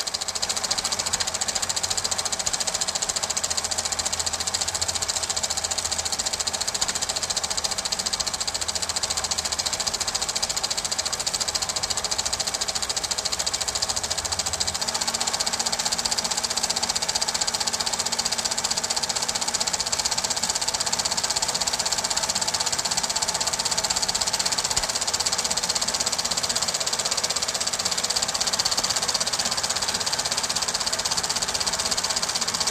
Hier schon einmal ein wenig akustische Nostalgie für Ihr erstes Projekt: Projektor.mp3
projector.mp3